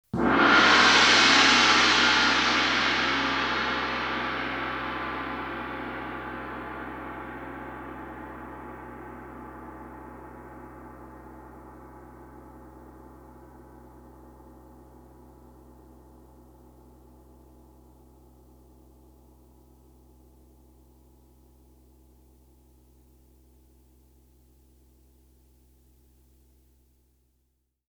Kumistin | Lataa ääniefektit .mp3.
Kumistin | äänitehoste .mp3 | Lataa ilmaiseksi.